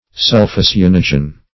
Sulphocyanogen \Sul`pho*cy*an"o*gen\, n. (Chem.)